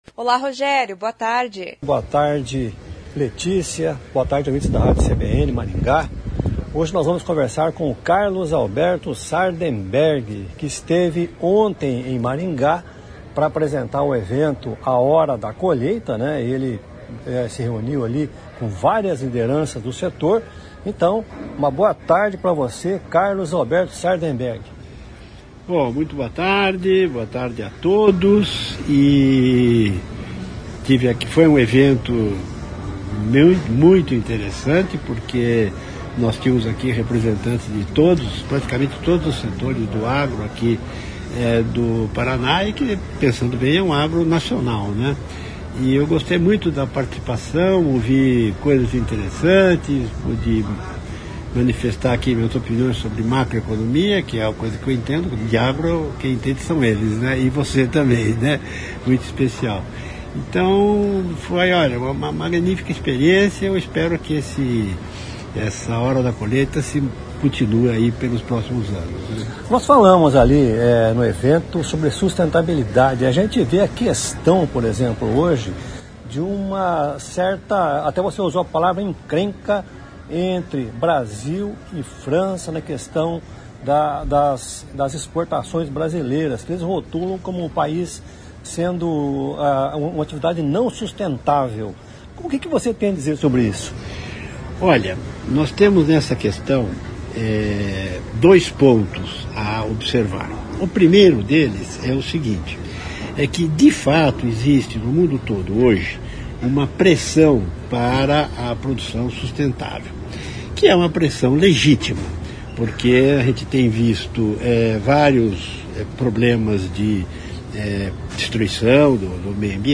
O CBN Rural conversou com o âncora e colunista do CBN Brasil, Carlos Alberto Sardenberg, sobre a estranha abertura da Agrishow, uma das maiores feiras tecnológicas do agronegócio mundial.